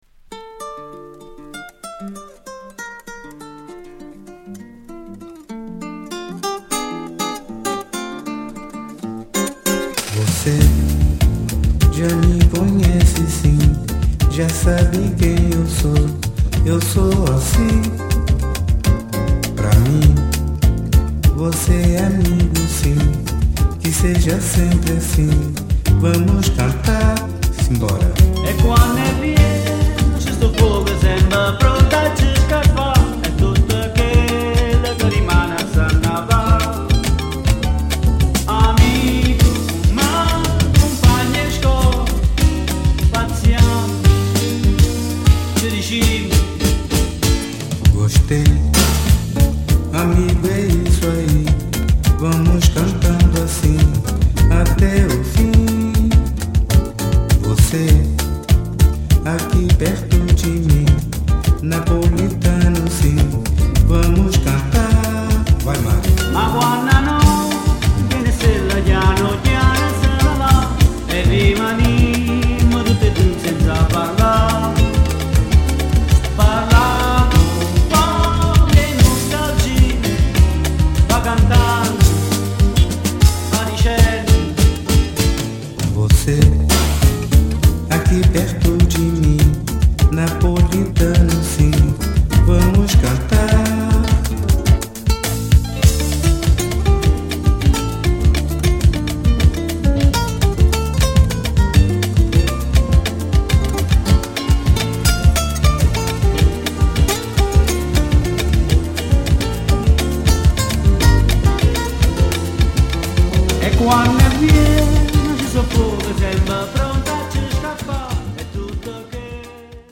Bossa / Samba italy